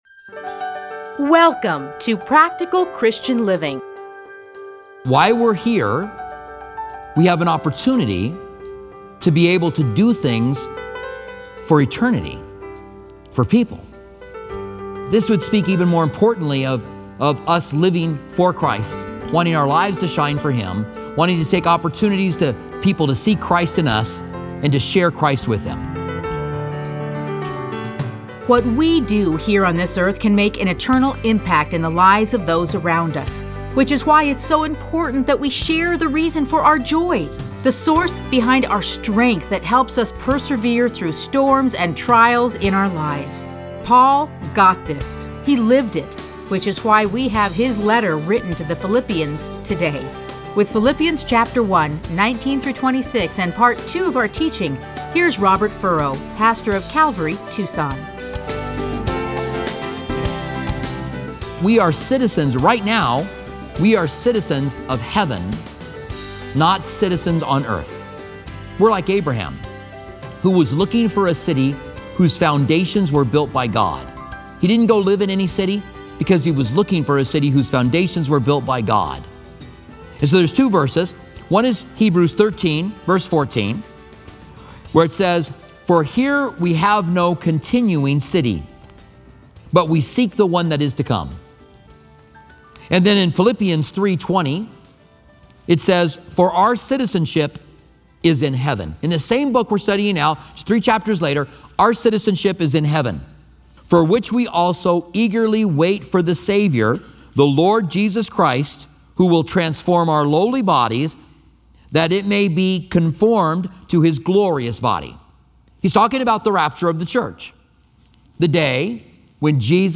Listen to a teaching from A Study in Philippians 1:19-26.